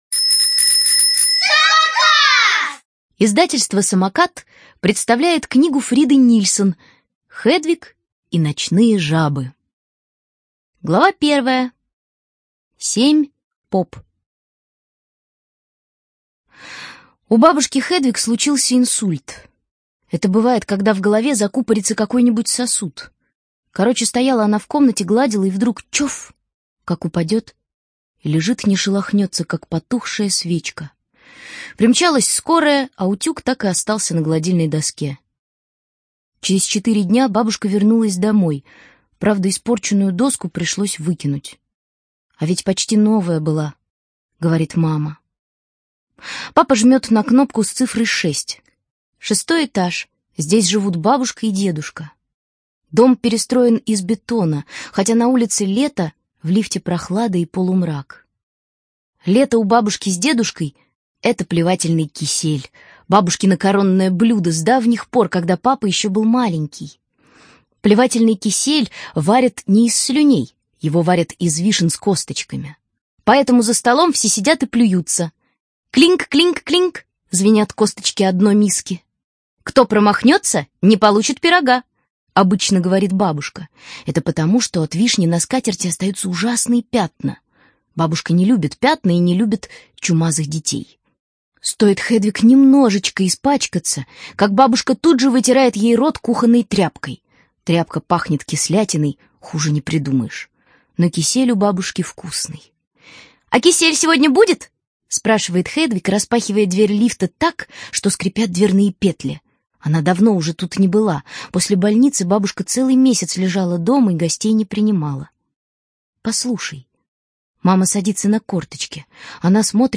Студия звукозаписиСамокат